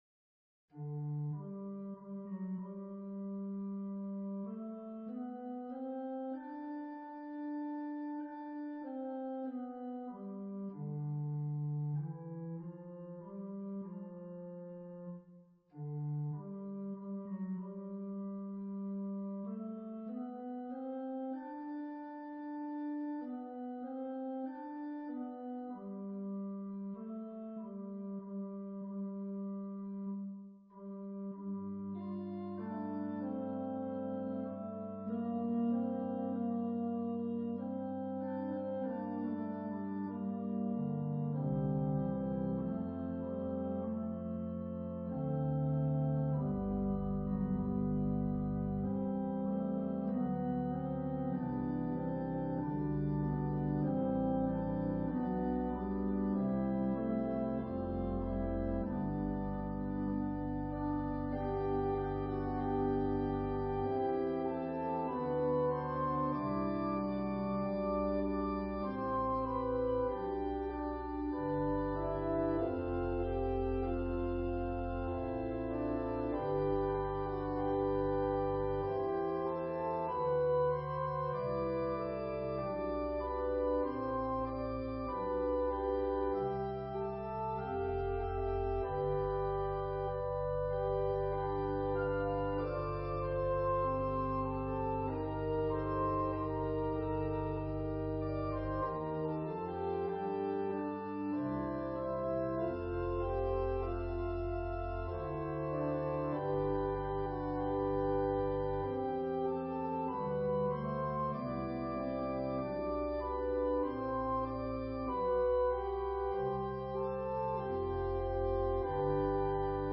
Organ/Organ Accompaniment
An organ solo version of Mack Wilberg's choral arrangement.